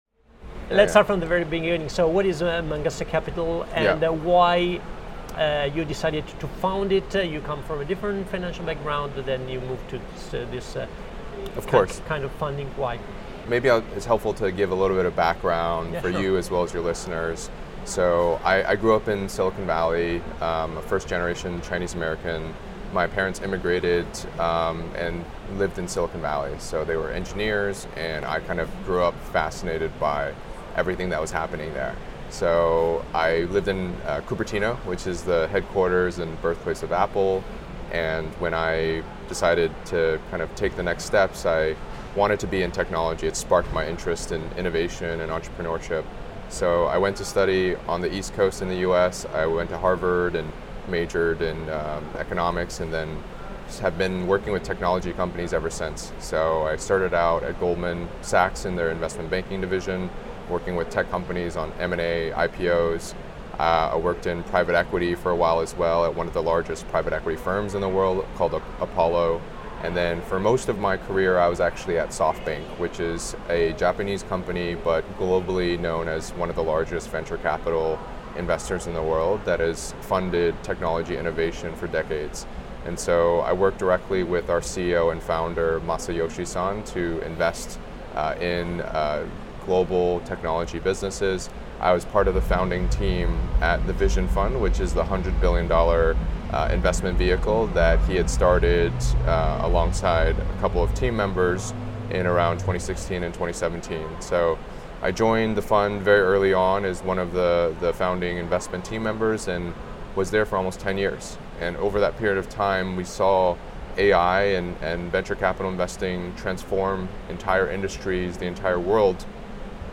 Alphaville Intervista